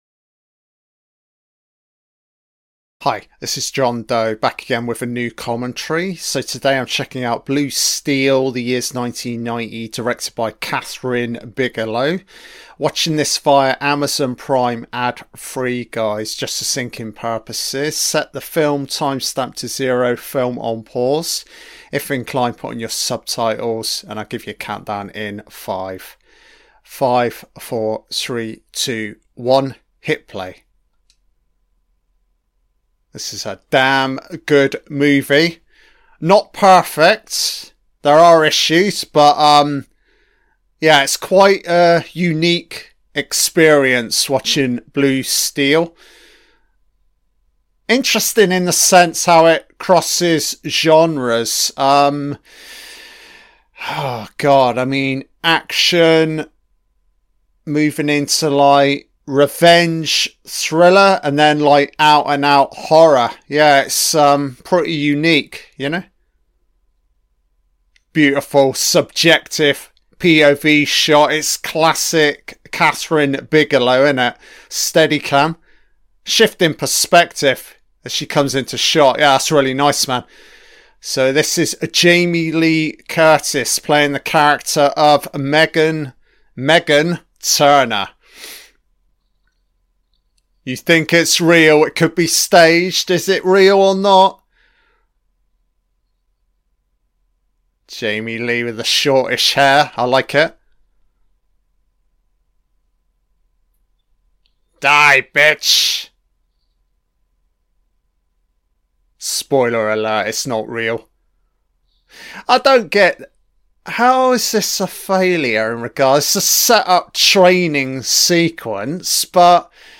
An Audio-Only Commentary on the 1990 film BLUE STEEL